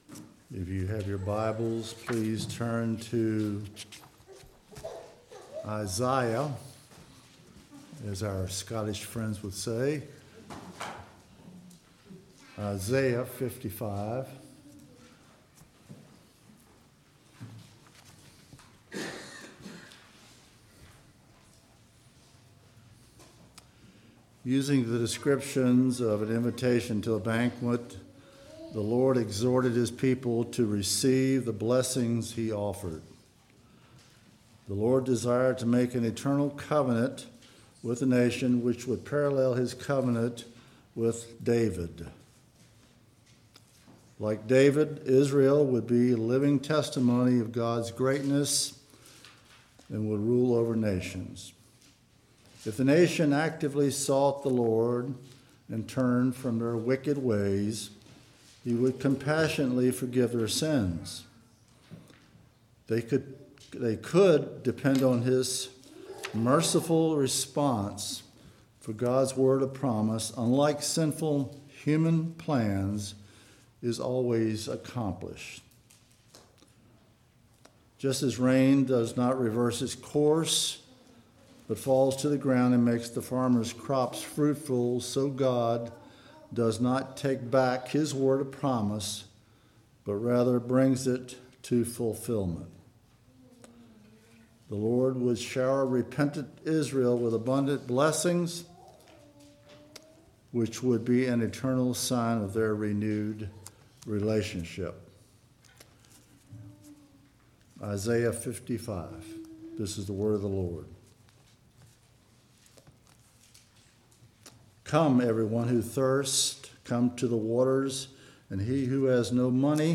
Passage: 1 Corinthians 13:1-8 Service Type: Sunday Morning